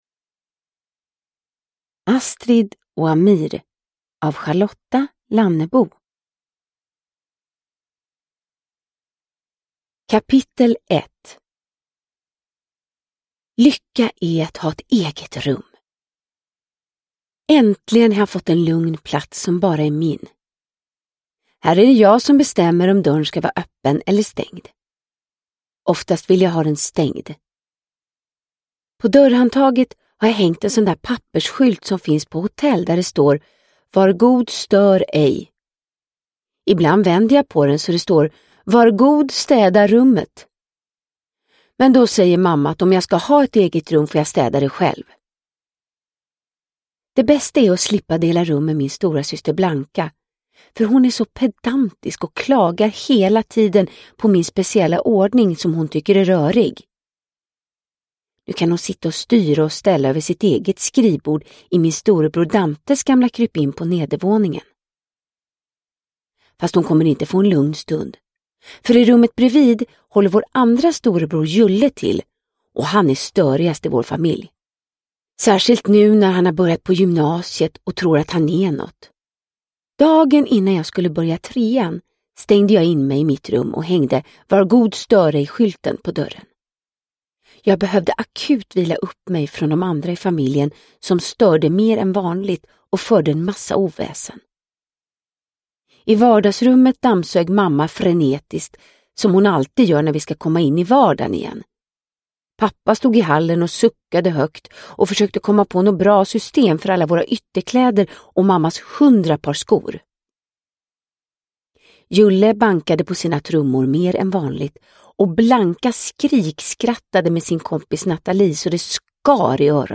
Astrid & Amir – Ljudbok – Laddas ner